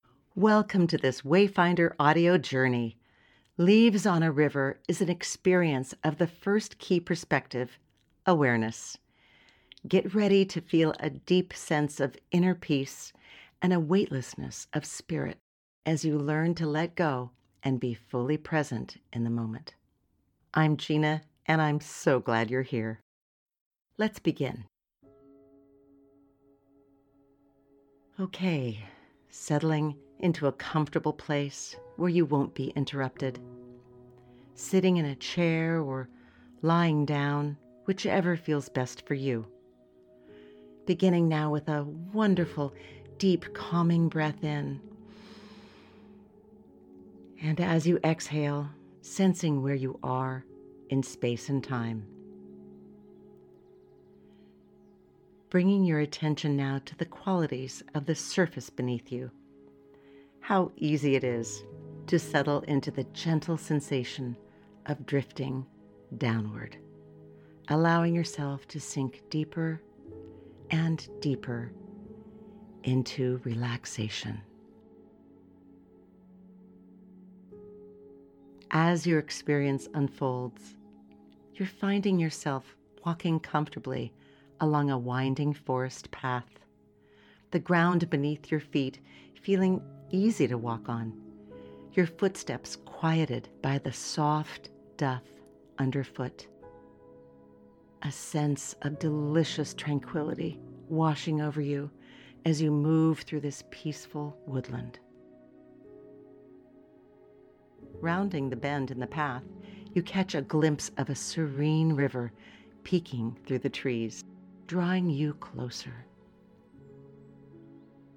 Guided Visualization